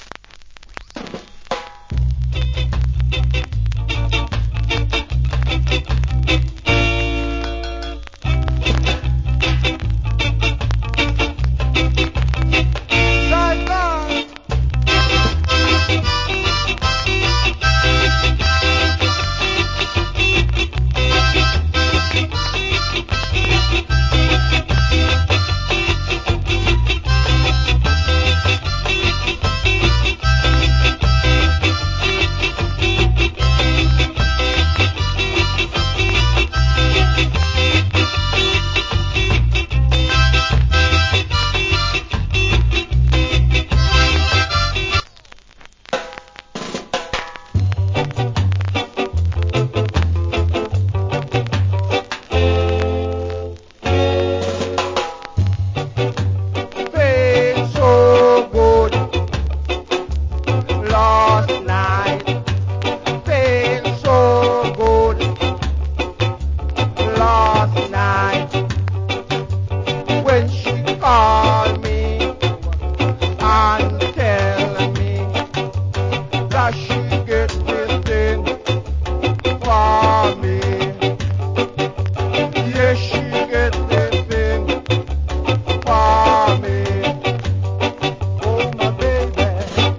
Killer Harmonica Inst.